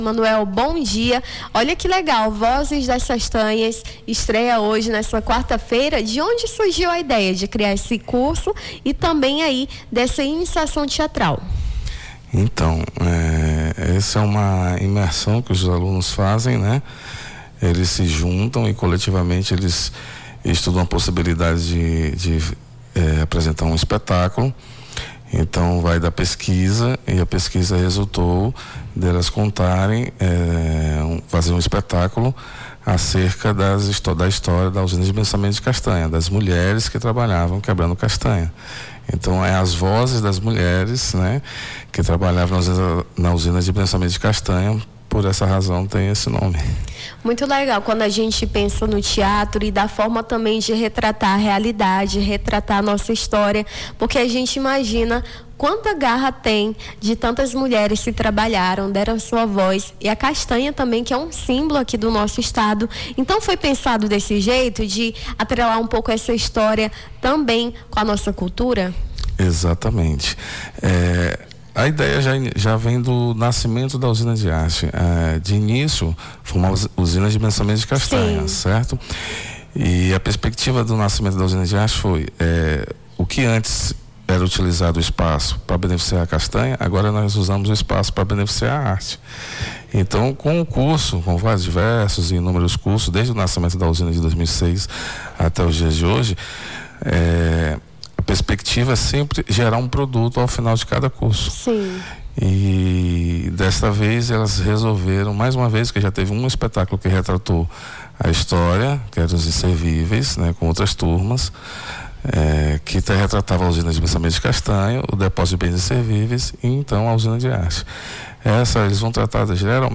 Nome do Artista - CENSURA - ENTREVISTA (VOZES DAS CASTANHAS) 02-07-25.mp3